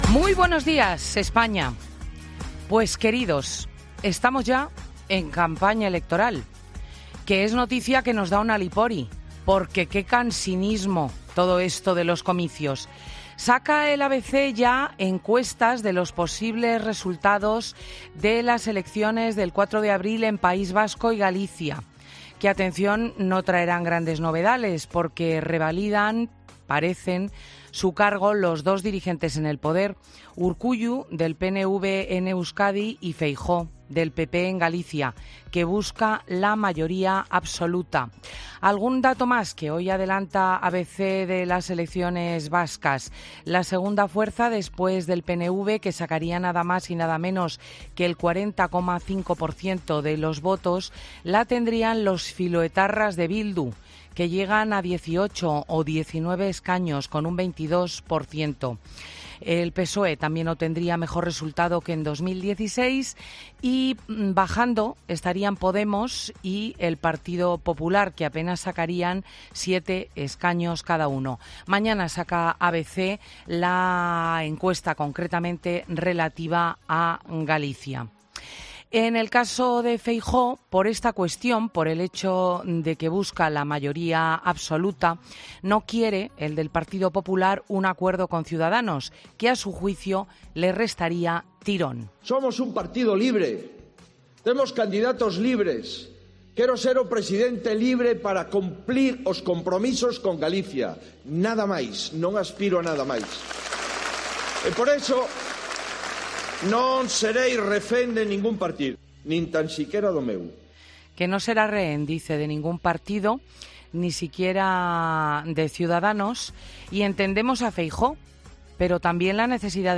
La presentadora de 'Fin de Semana' analiza el Comité Federal del PSOE, las elecciones vascas y la guerra tecnológica en su monólogo de este domingo